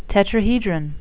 (tet-tra-he-dron)